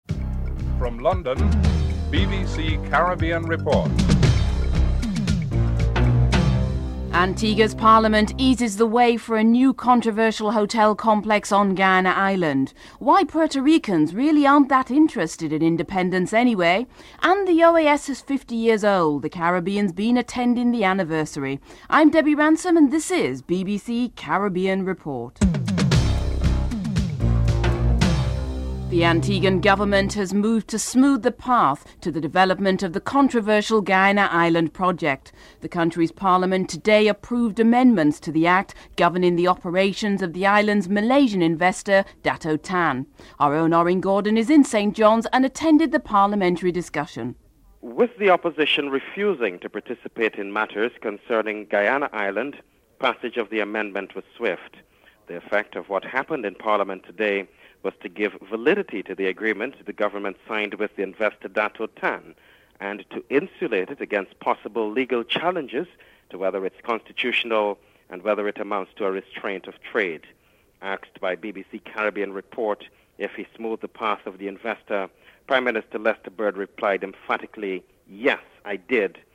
Prime Minister Denzil Douglas called for a unified stance by the countries of the ACP group (09:10-12:08)